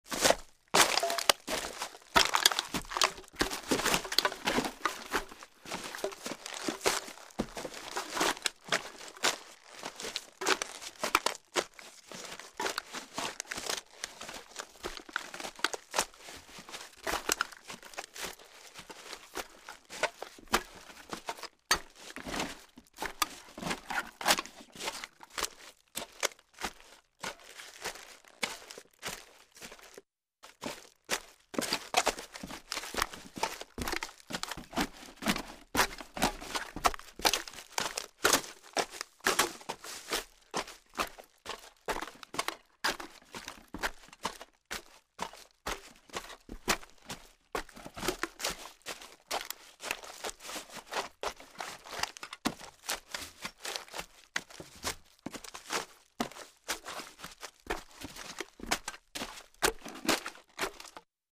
Шаги по мусору nДвижение сквозь отходы nСледы на мусоре nМаршрут среди хлама